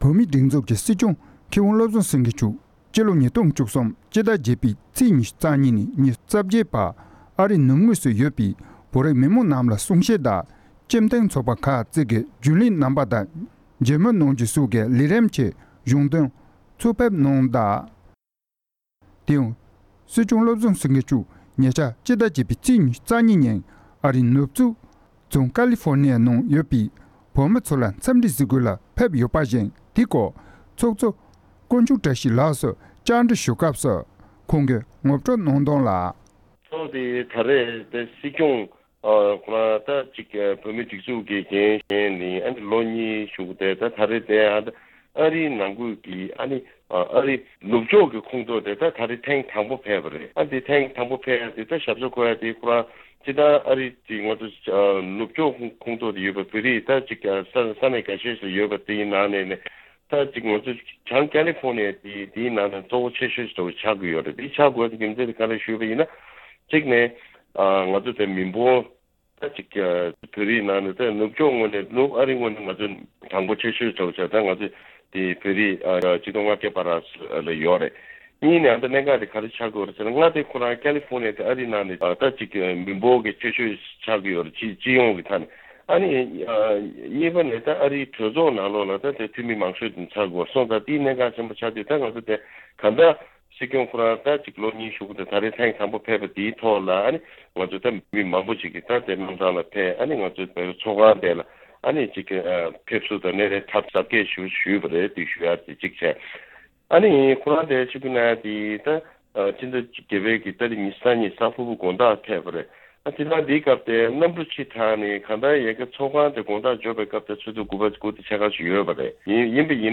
ས་གནས་བོད་རིགས་སྤྱི་མཐུན་ཚོགས་པའི་ཚོགས་གཙོ་སོགས་ལ་གནས་འདྲི་ཞུས་པའི་ལེ་ཚན་ཞིག་